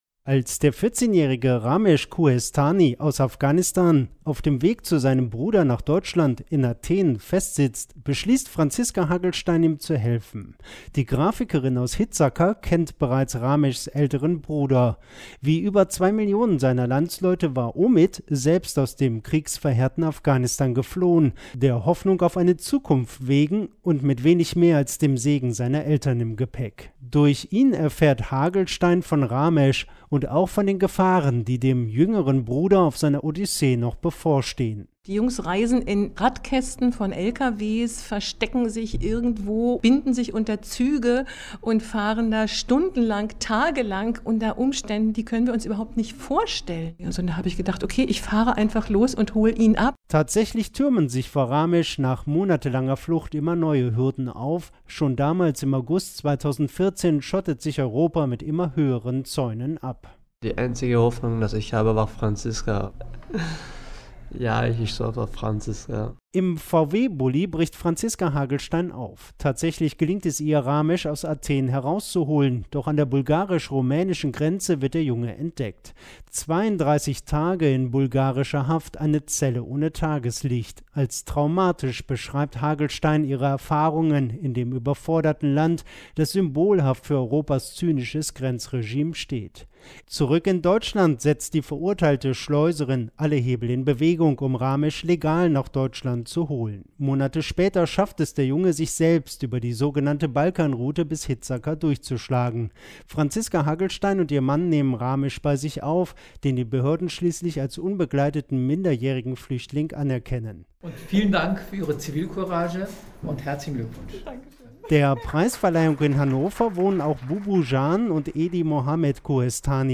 Fluchthilfepreis-DLR-Studio-9.mp3